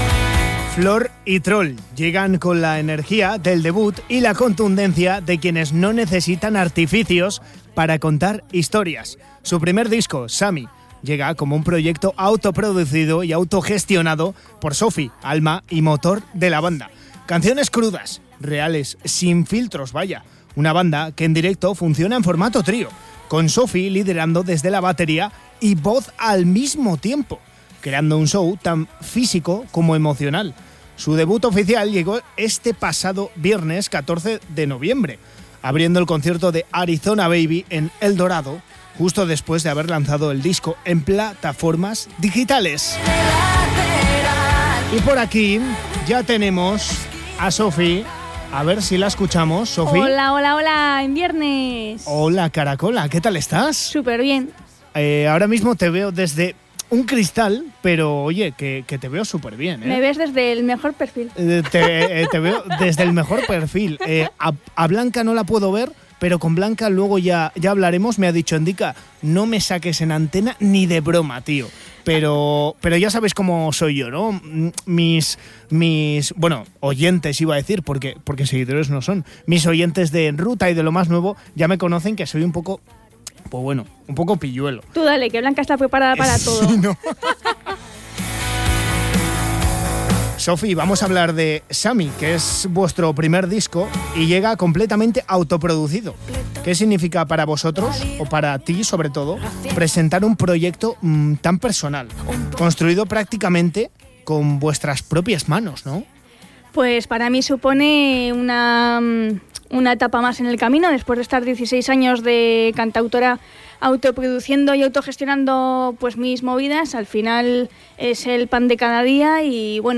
ENTREVISTA-FLOR-Y-TROL.mp3